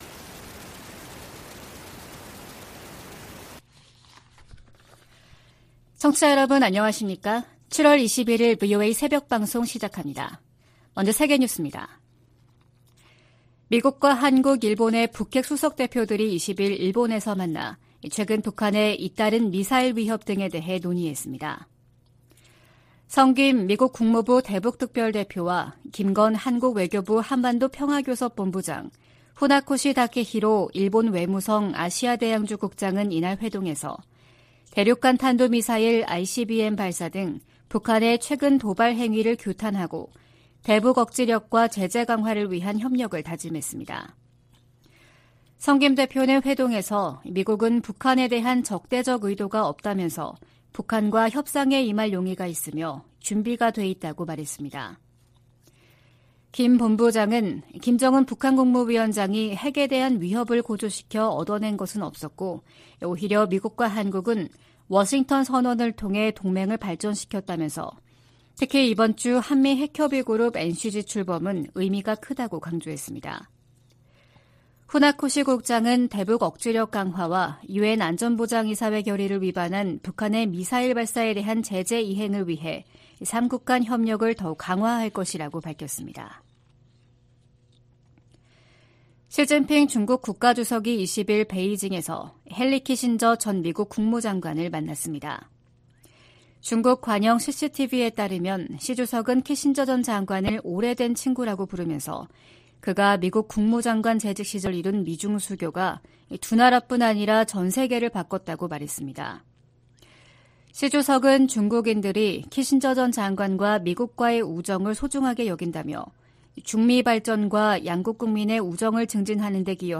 VOA 한국어 '출발 뉴스 쇼', 2023년 7월 21일 방송입니다. 미국과 한국, 일본 정상회의가 다음달 캠프데이비드에서 열립니다.